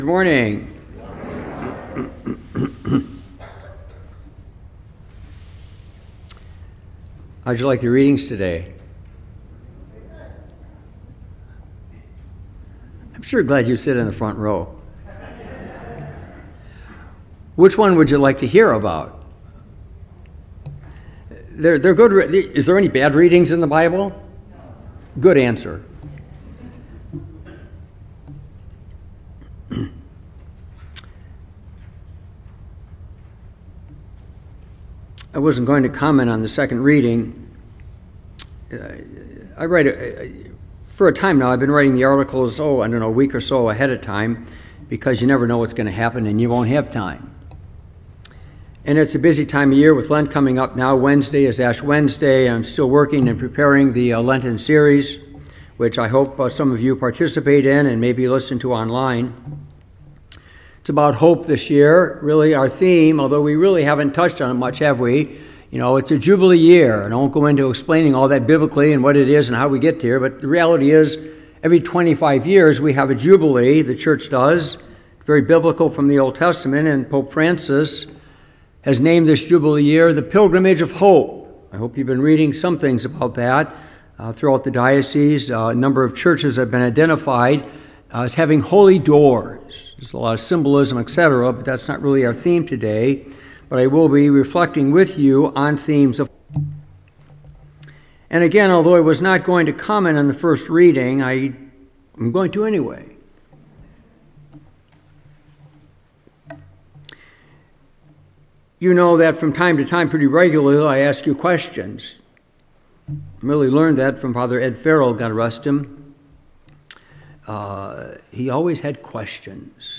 Homily, March 9, 2025
Recent Sermons